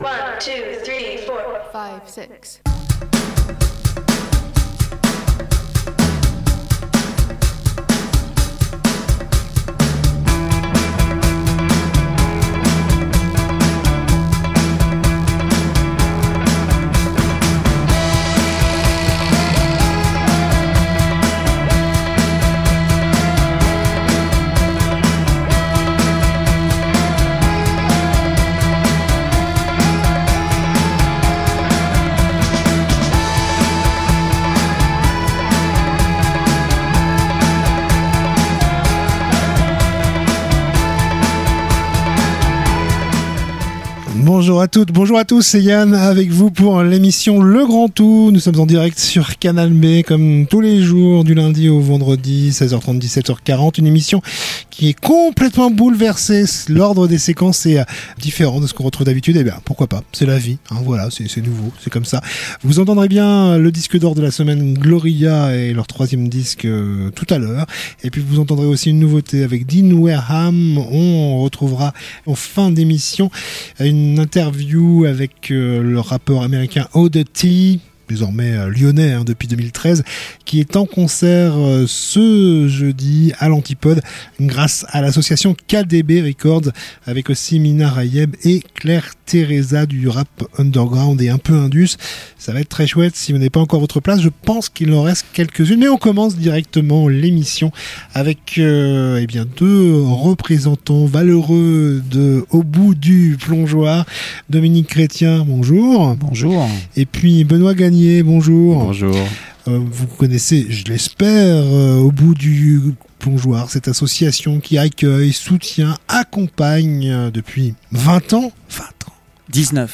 itv musique / infos-concerts